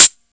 Closed Hats
Hat (28).wav